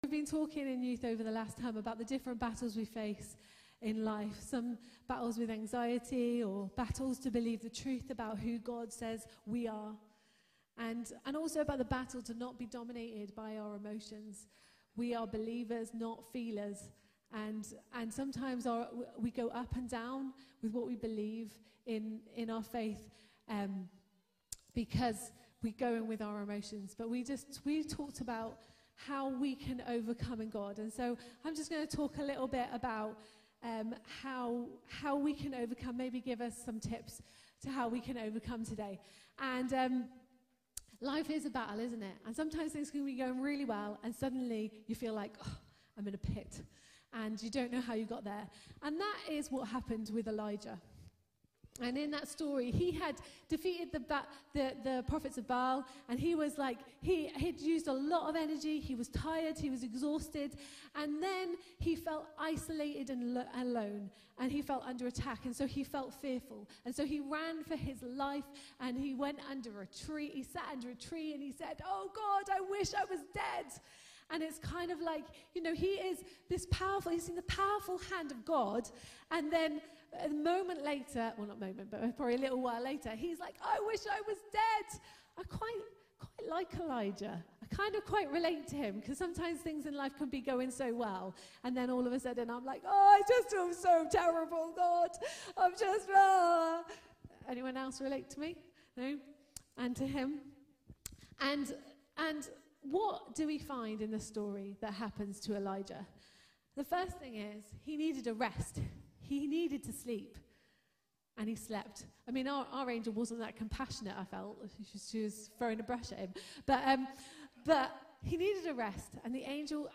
Youth-Service-Message-Overcoming-in-the-Battle.mp3